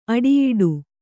♪ aḍiyiḍu